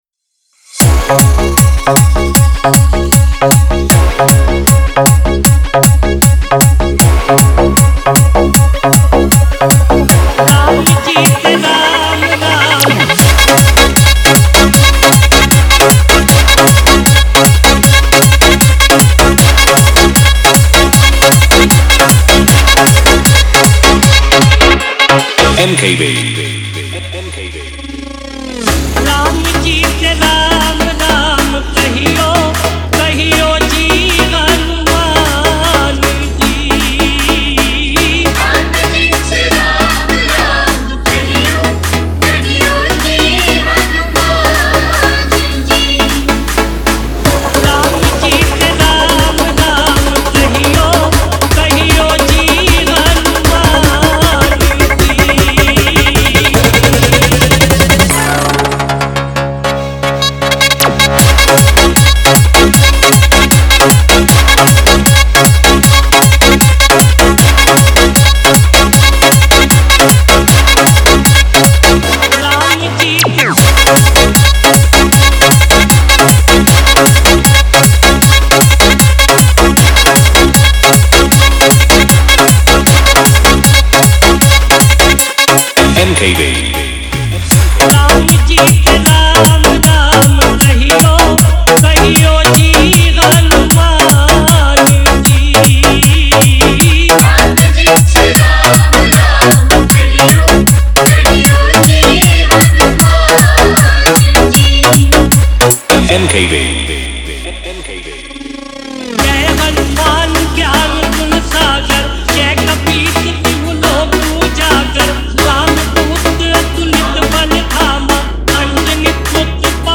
electrifying Bhakti Drop Mix
Desi Trap Bhajan, Bhakti EDM Remix
Devotional DJ Remix